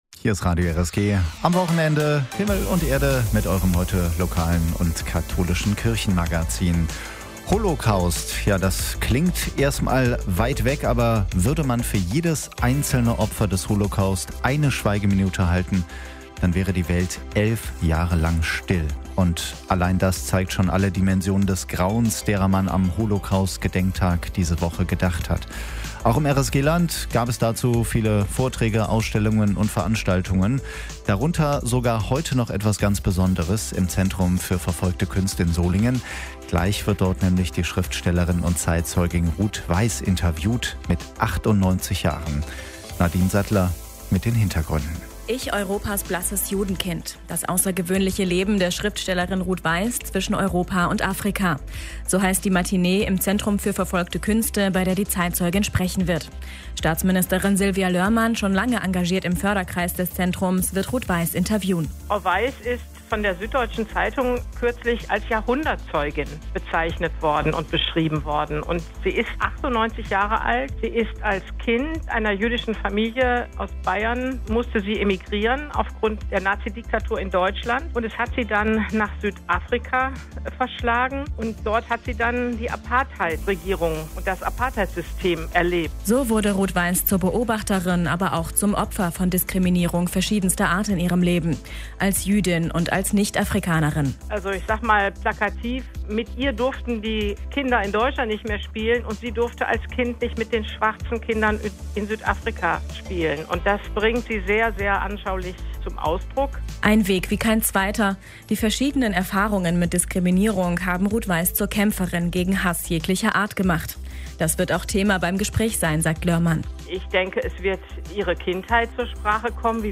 Kampf gegen Hass: Die Zeitzeugin Ruth Weiss im Gespräch